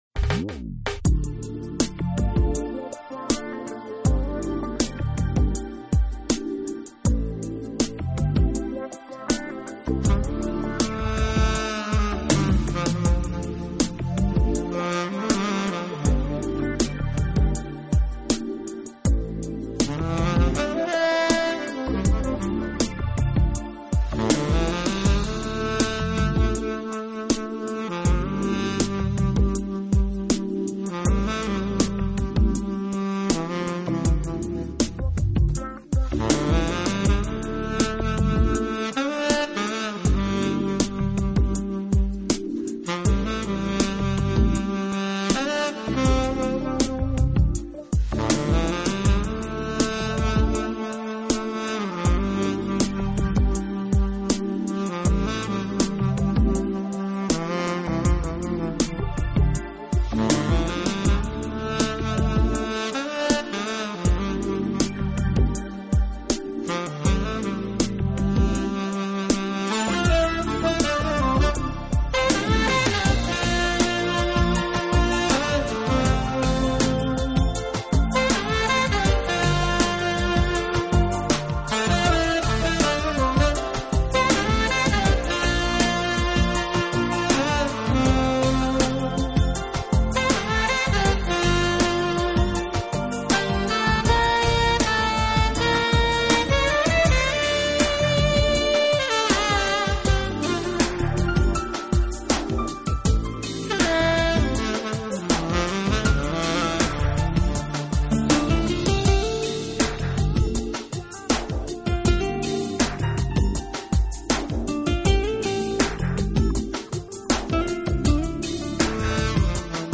音乐类型：Smooth Jazz